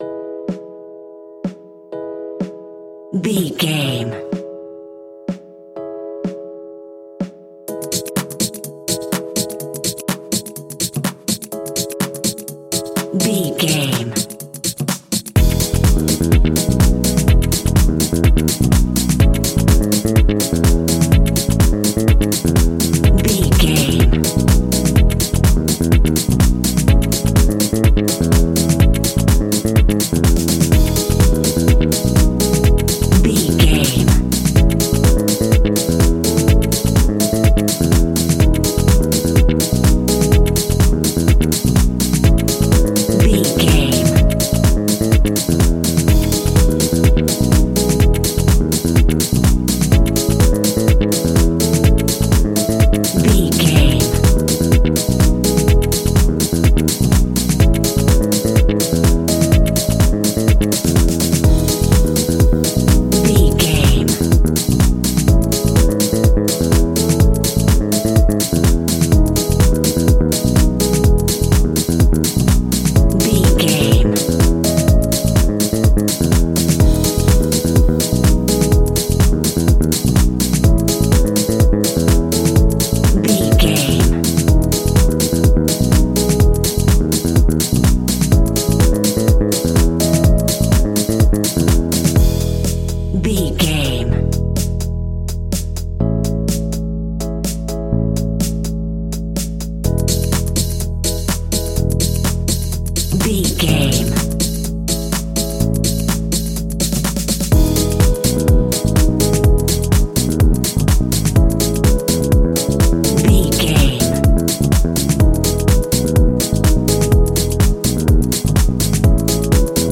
royalty free music
Ionian/Major
uplifting
energetic
bouncy
bass guitar
saxophone
electric piano
drum machine
synth
groovy